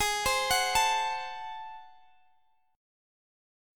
Ab7b9 Chord
Listen to Ab7b9 strummed